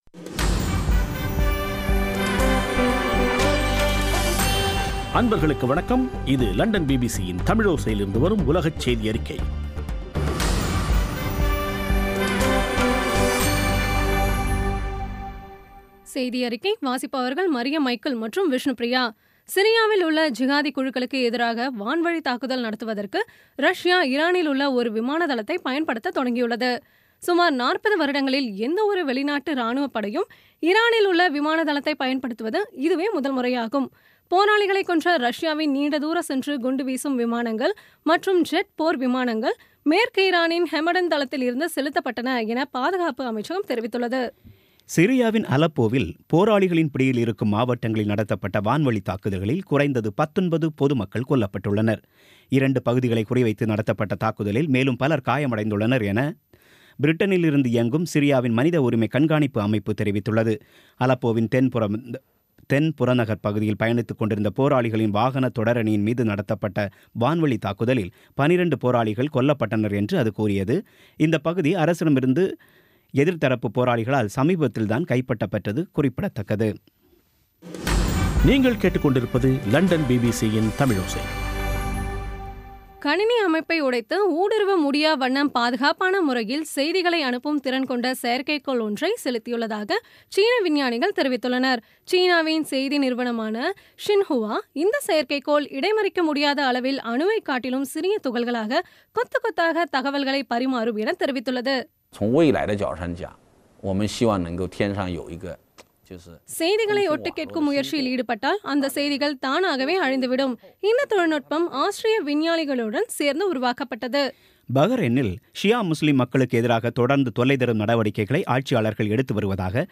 இன்றைய (ஆகஸ்ட் 16-ஆம் தேதி ) பிபிசி தமிழோசை செய்தியறிக்கை